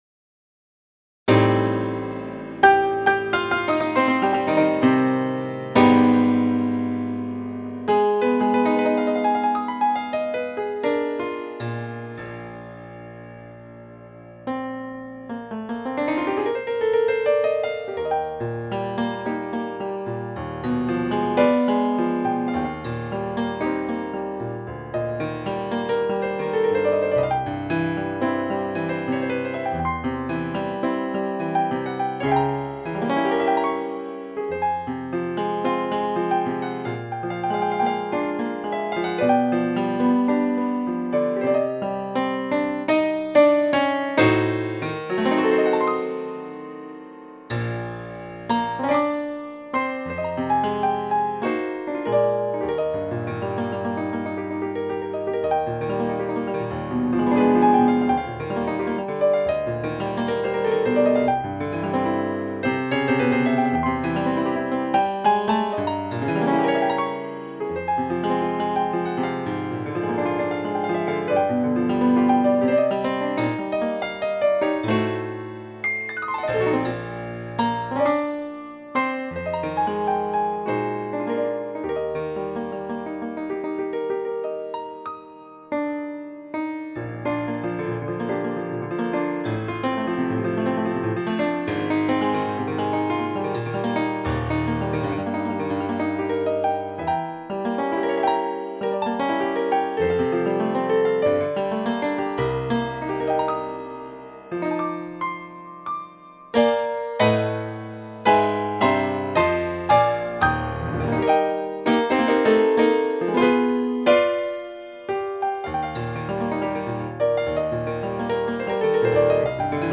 Classic　Piano　etc